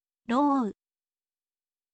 rou ou